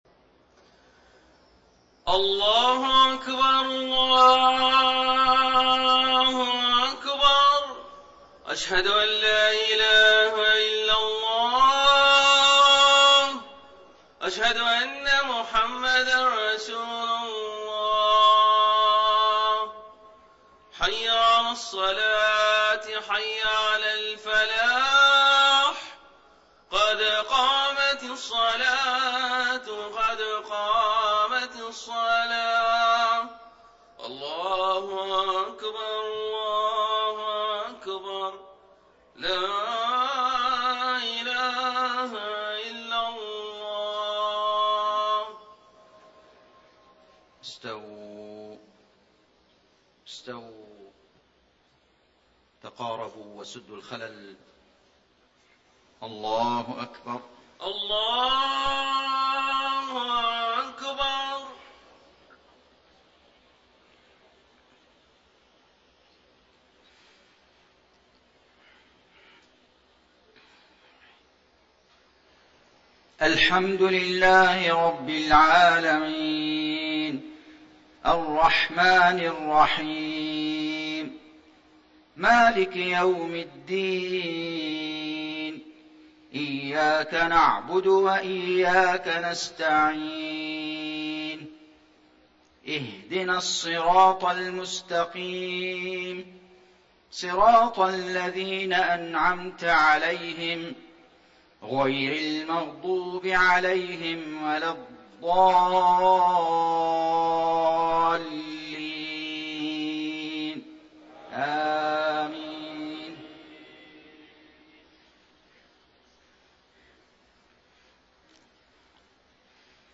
صلاة المغرب 2-6-1435 سورتي العاديات و النصر > 1435 🕋 > الفروض - تلاوات الحرمين